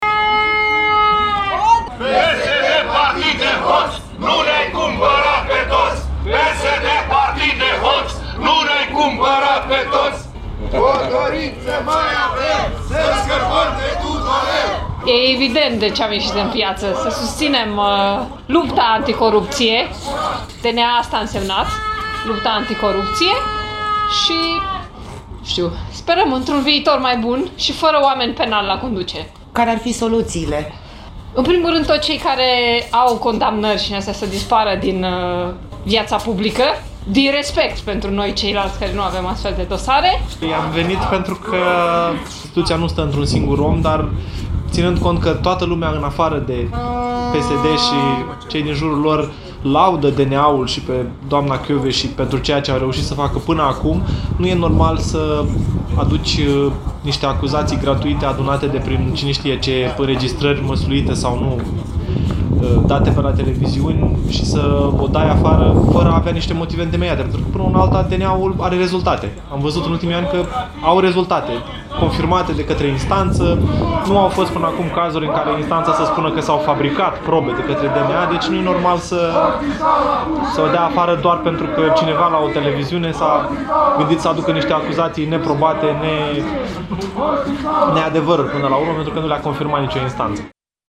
De asemenea, la Iași, 200 de oameni strigă împotriva PSD și ALDE.